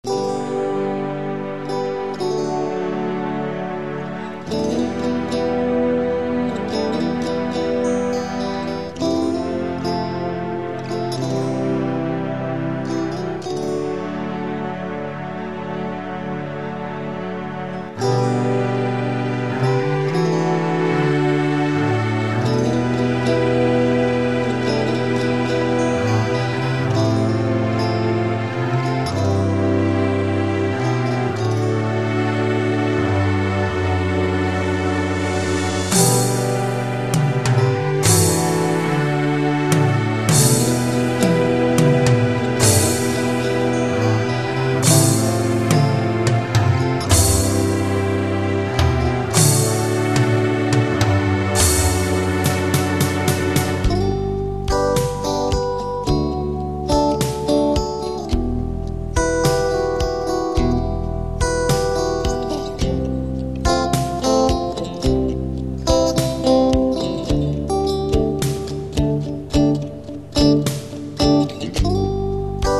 La guitare utilisée est la RS850 :
Guitare branchée en direct sur la carte son de l'époque, sans ampli guitare
Supers samples en tout cas, à tous les niveaux (ca fait un peu rock progressif du siècle dernier, avis personnel).